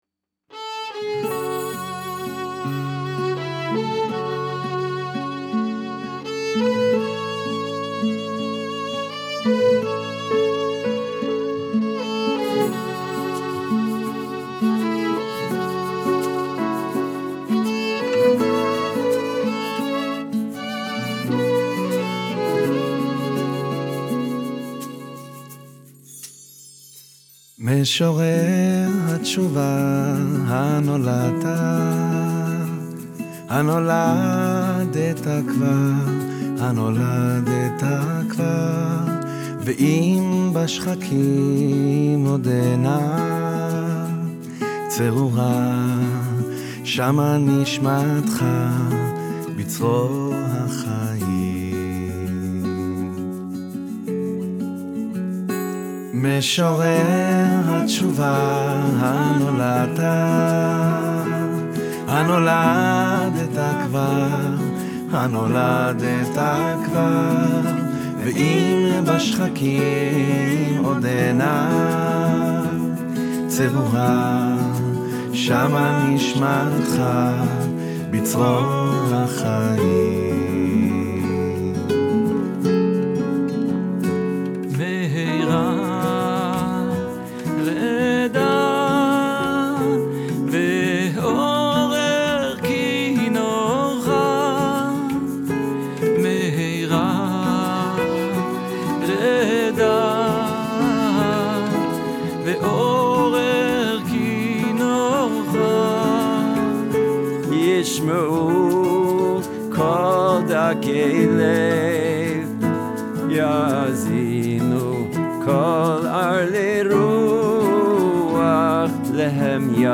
התכנסו לאולפן ההקלטות הזמרים והחברים